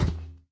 minecraft / sounds / mob / irongolem / walk4.ogg
walk4.ogg